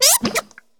Cri de Coiffeton dans Pokémon HOME.